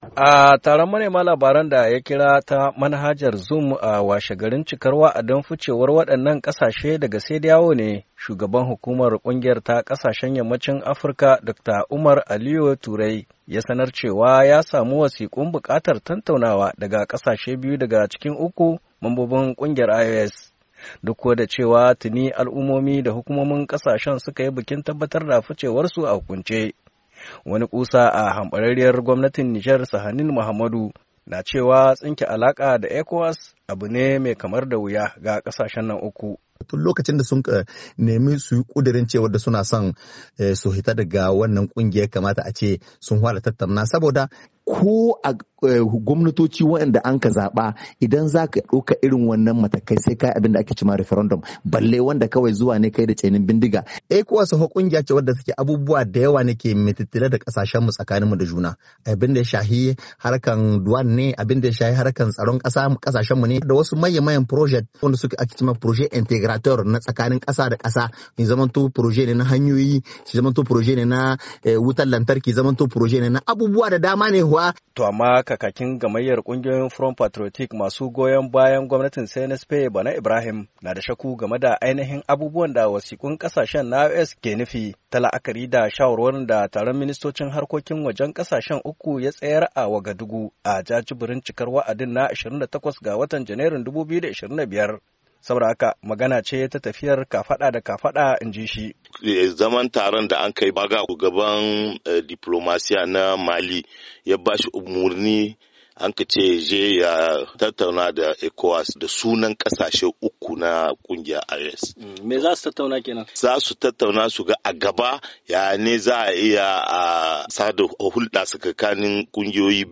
N'iamey, Nijar. —